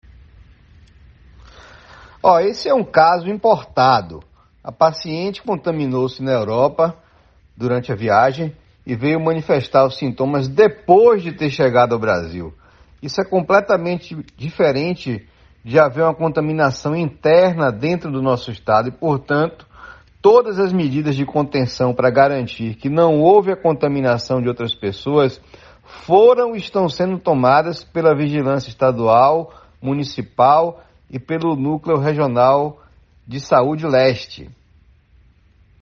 Ouça o áudio do secretário de Saúde do Estado da Bahia, Fábio Vilas Boas: